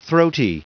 Prononciation du mot throaty en anglais (fichier audio)
Prononciation du mot : throaty